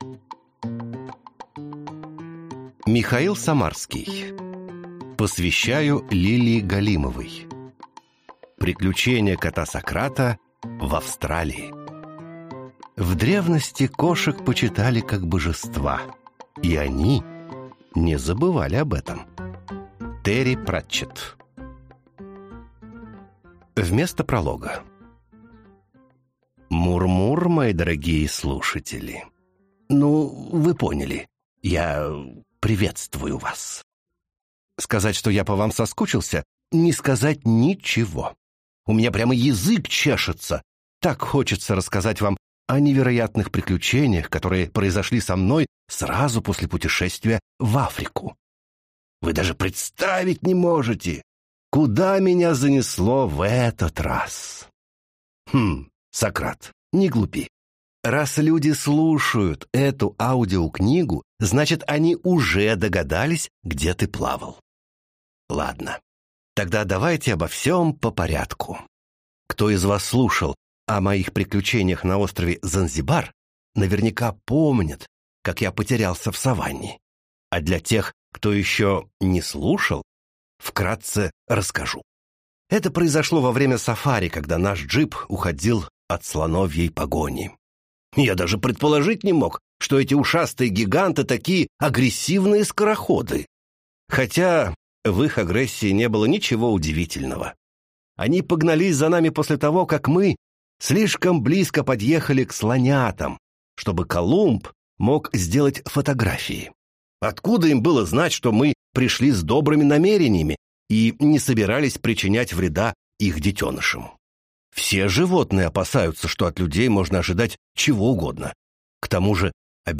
Аудиокнига Приключения кота Сократа в Австралии | Библиотека аудиокниг